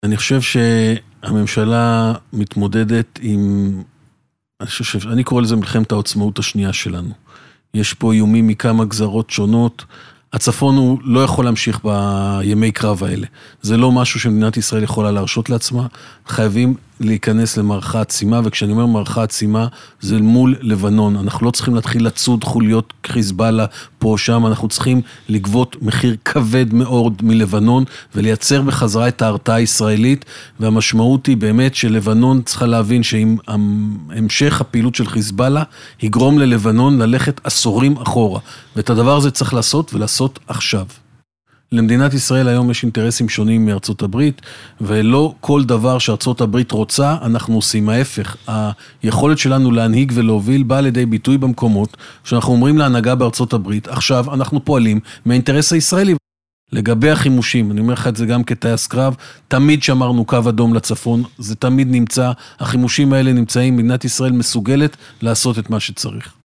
השר קיש בריאיון בתוכנית "הנבחרים" ברדיוס 100FM